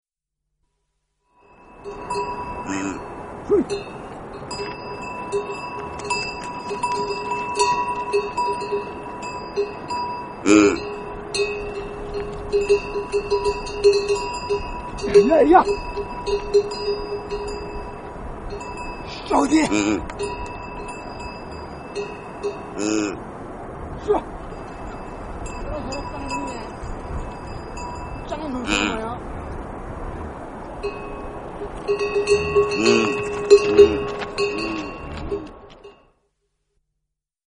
Пастух и як среди гор Непала